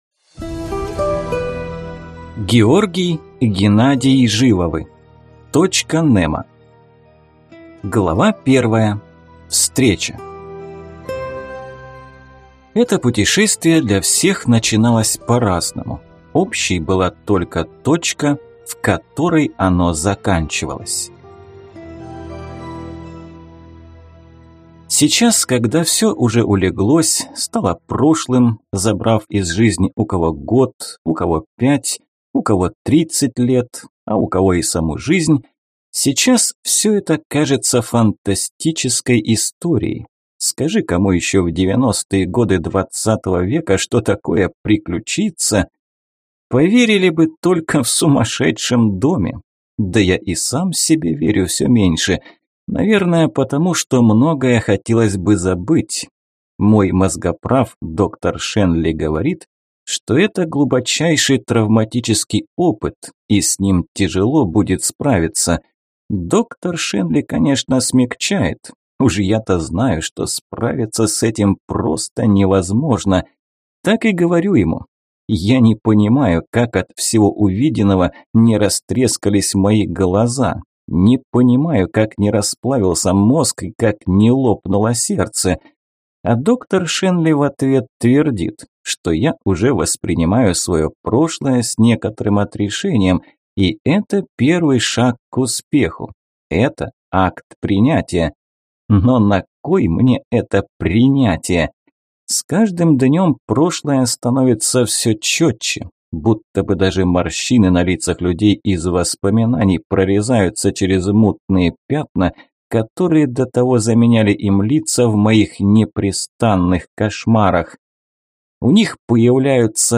Аудиокнига Точка Немо | Библиотека аудиокниг